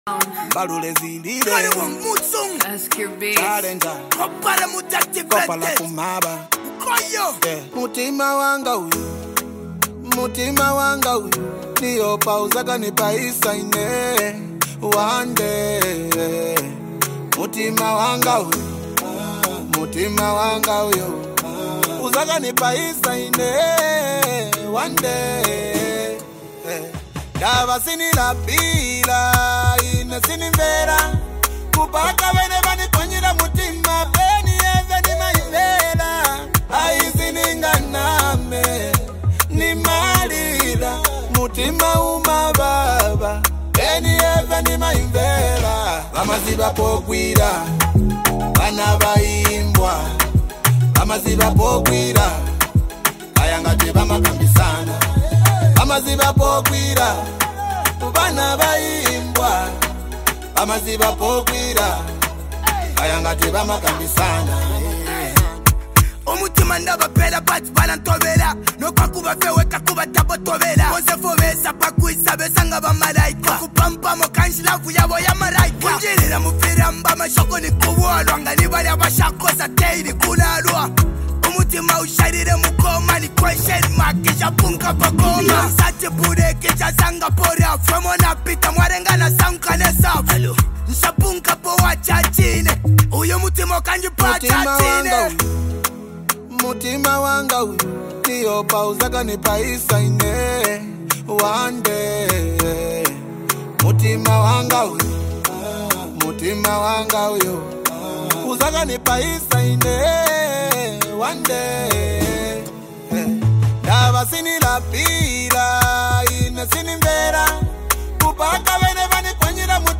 A zambian music talented copperbelt music duo of